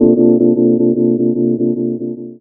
Tremo_Rhodes.wav